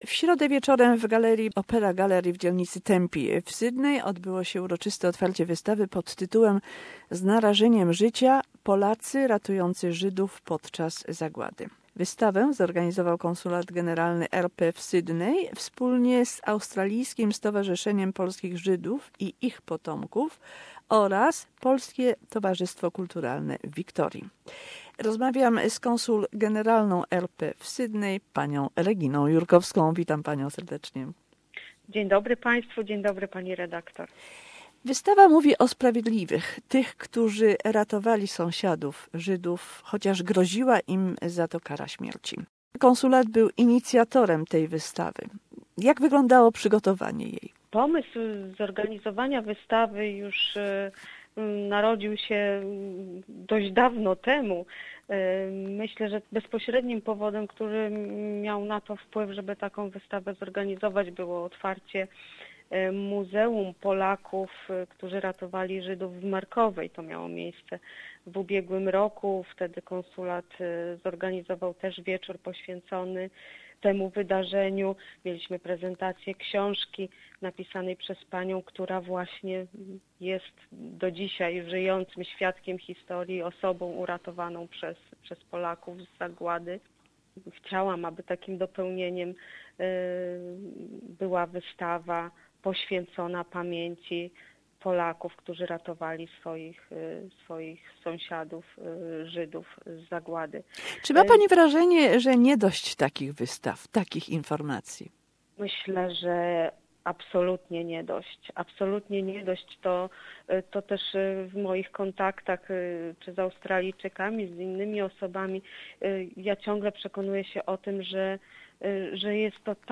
Interview with Consul General of Poland Regina Jurkowska